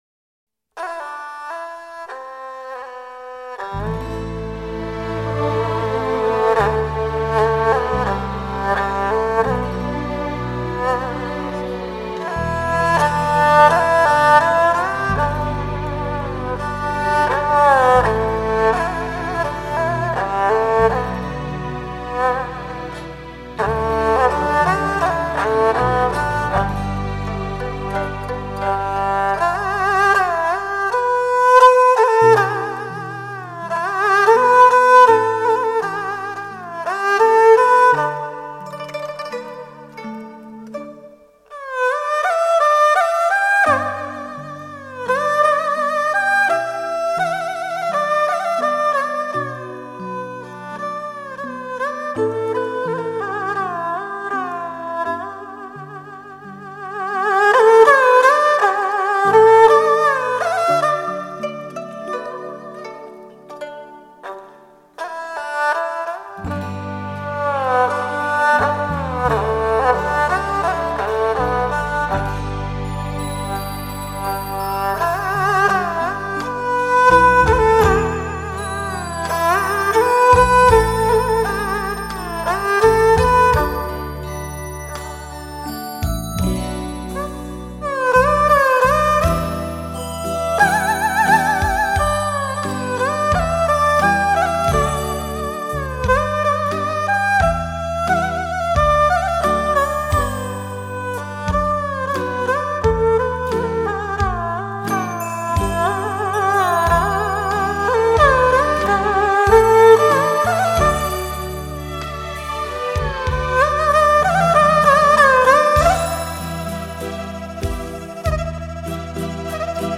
首页 背景音乐 其他音乐 正文
该BGM音质清晰、流畅，源文件无声音水印干扰